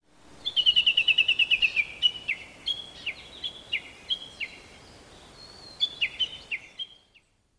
ウグイスには、
「谷渡り」と呼ばれている鳴き方がありますが、この個体はその谷渡りと次の谷渡りとの間の３分１４秒の間に１６回もホーホケキョと続けて鳴きました。